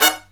HIGH HIT14-L.wav